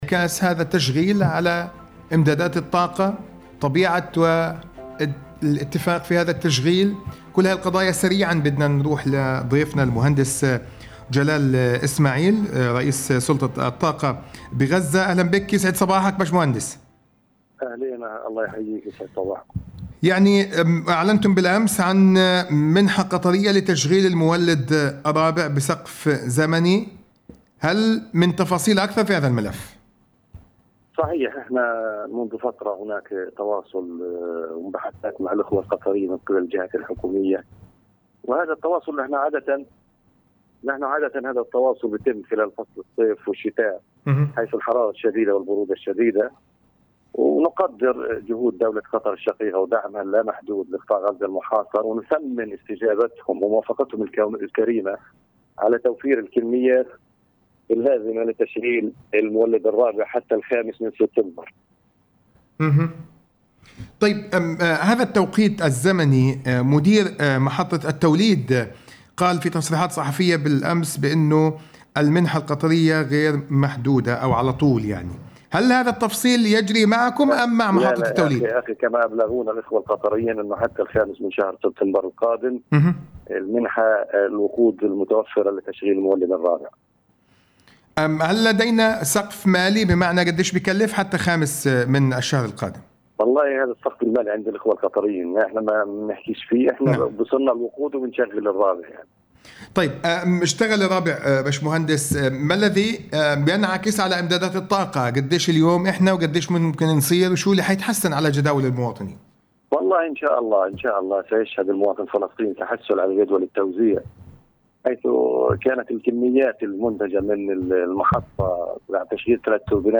عبر راديو الشباب.. مقابلة إذاعية مع رئيس سلطة الطاقه بغزة - غزة بوست
عبر راديو الشباب.. مقابلة إذاعية مع رئيس سلطة الطاقه بغزة